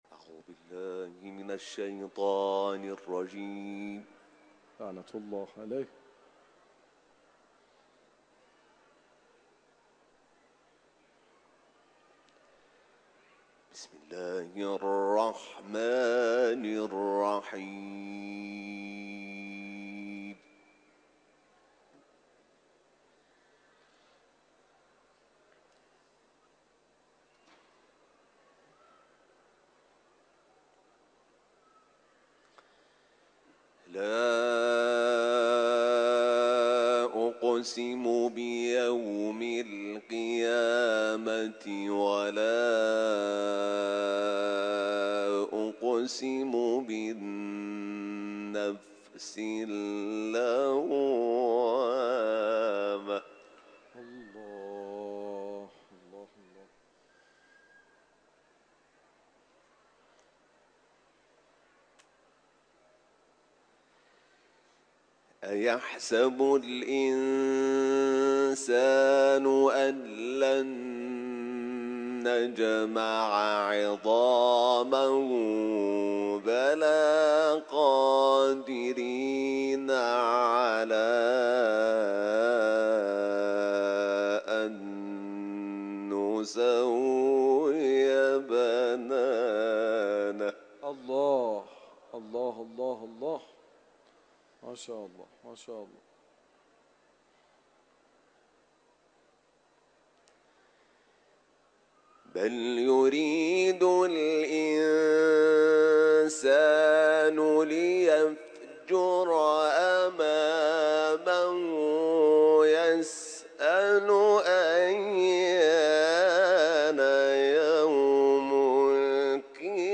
حمیدشاکرنژاد، قاری بین‌المللی قرآن، در محفل انس با قرآن حرم مطهر رضوی، آیات ۱ تا ۴۰ سوره «قیامت» را تلاوت کرده است که صوت این تلاوت تقدیم مخاطبان ایکنا می‌شود.
تلاوت